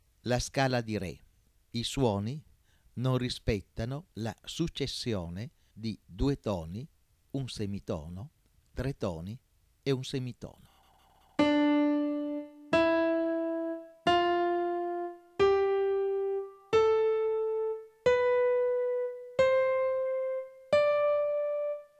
Ad esempio se prendiamo come suono base la nota Re e suoniamo i tasti bianchi avremo la seguente successione: tono, semitono, tono, tono, tono, semitono e tono.
04. Ascolto della scala di Re i cui suoni non rispettano la struttura della scala maggiore.